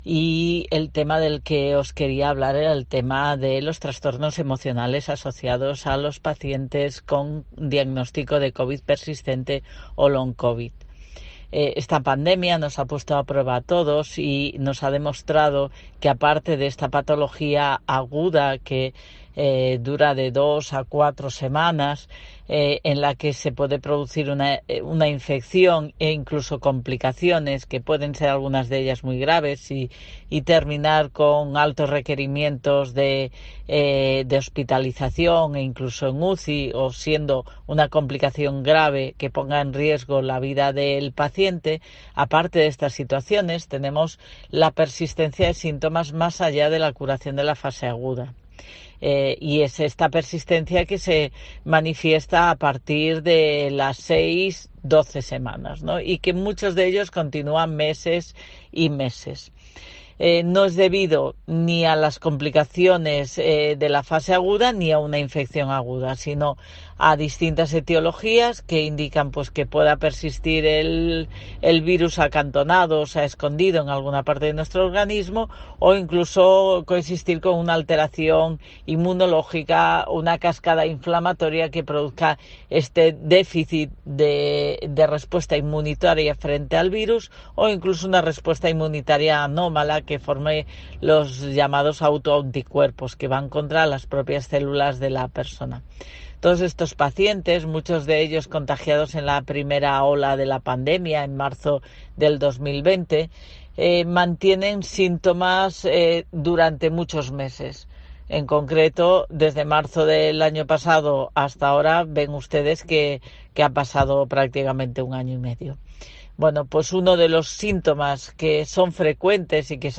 La nueva pandemia: una avalancha de pacientes con problemas psicológicos tras la COVID / doctora